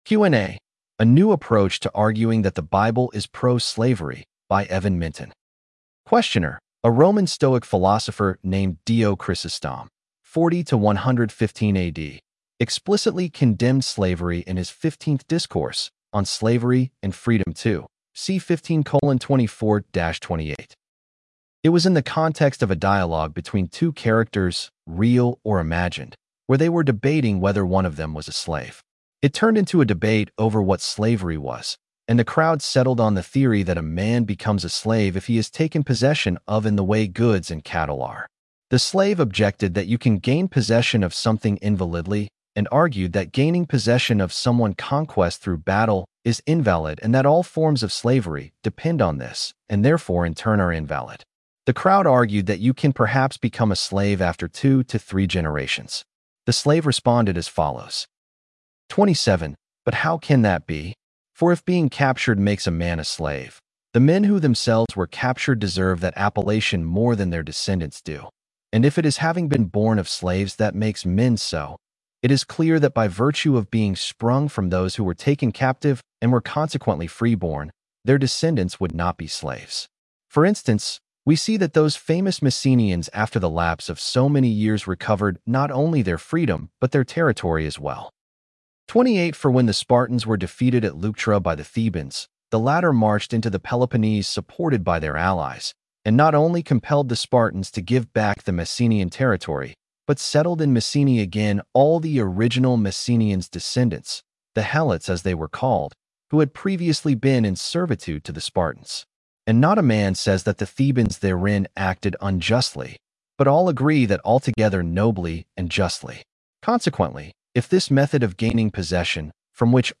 Audio accessibility for this blog post is powered by Microsoft Text-to-Speech technology.